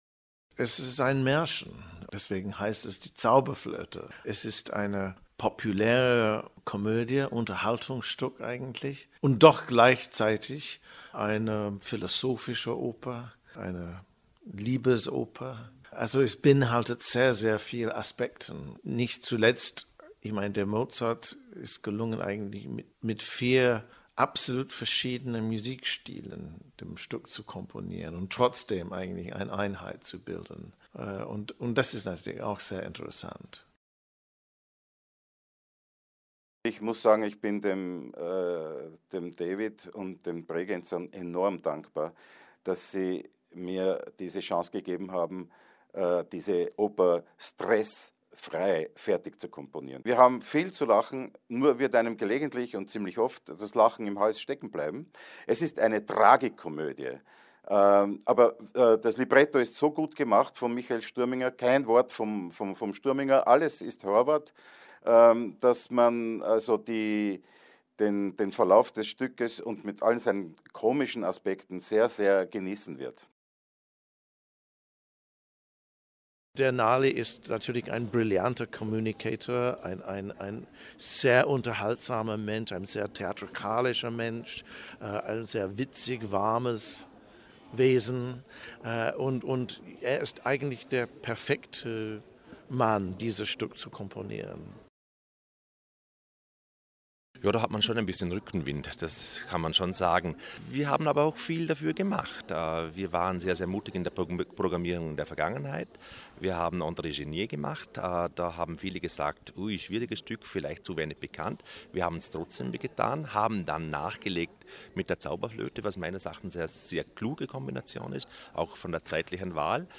Pressekonferenz Programmpräsentation 2014 - feature